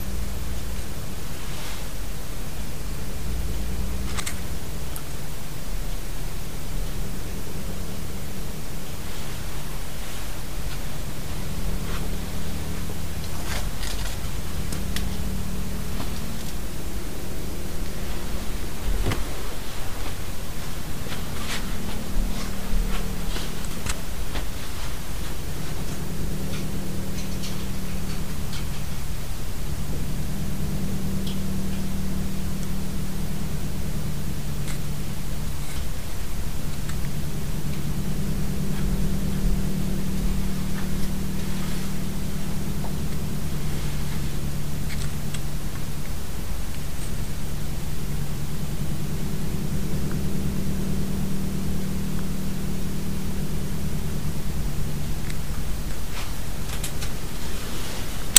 E-mail van een Burger – betreft ernstige geluidsoverlast door windmolens in de directe omgeving Nieuwolda_redacted 371 KB geluidsoverlast van sinds november geinstalleerde groter dan verwachte windmolens 1 MB